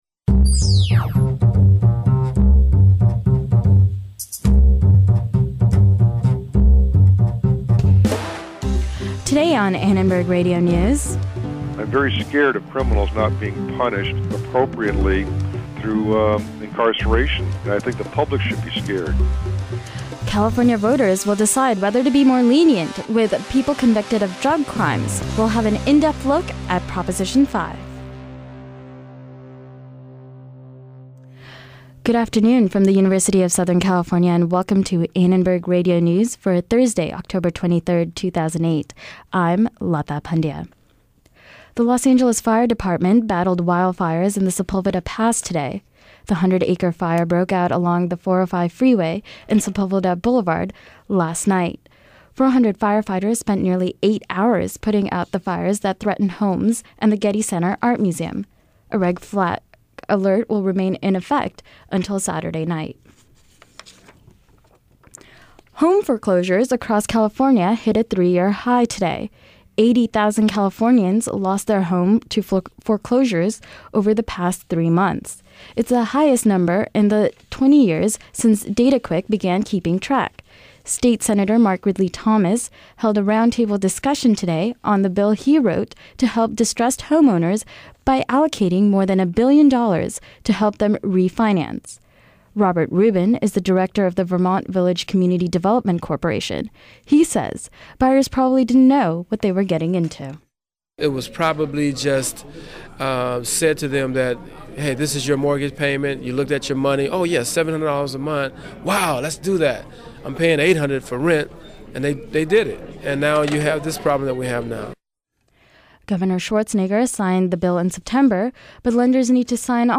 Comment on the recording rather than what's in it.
ARN Live Show - October 23, 2008 | USC Annenberg Radio News